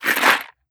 Ammo Pickup 003.wav